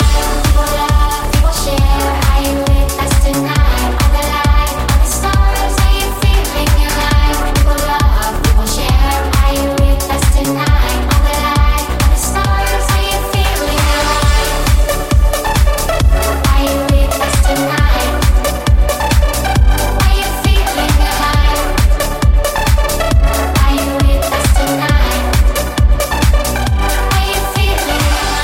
Genere: pop,dance,deep,disco,house.groove,latin,hit